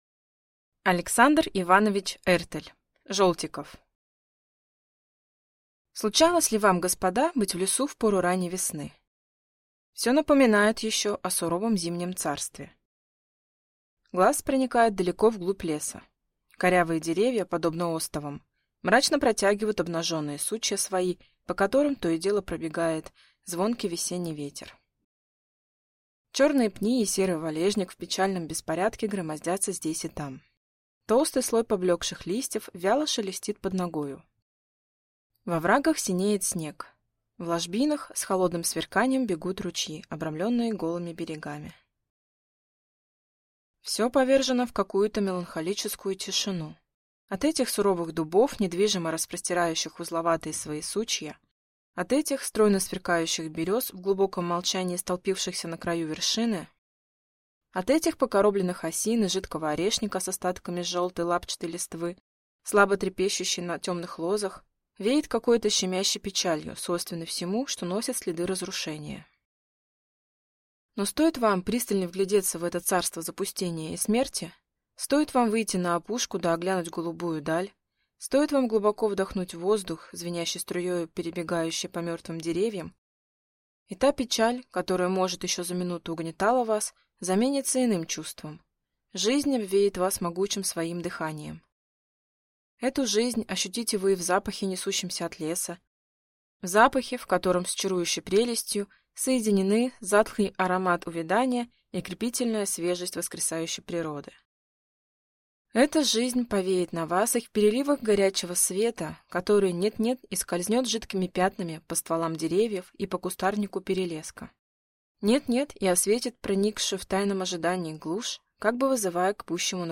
Аудиокнига Жолтиков | Библиотека аудиокниг